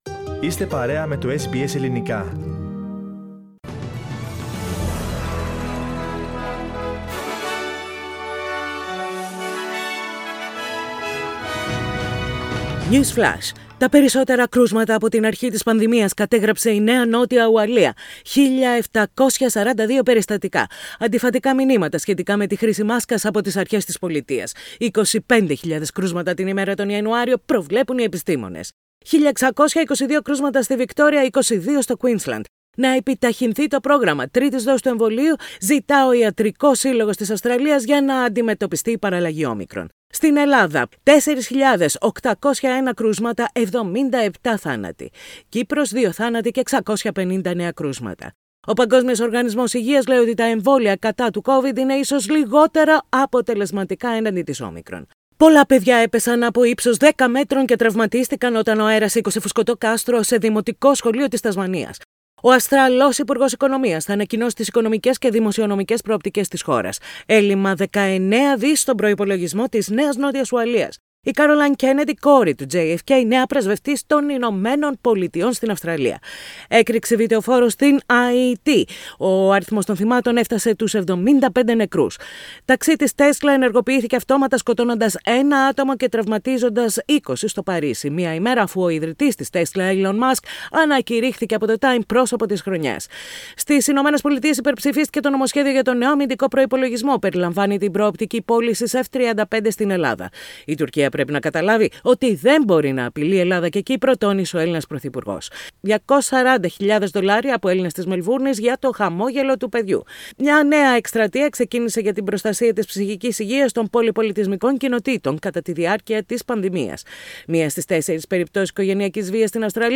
News Flash in Greek.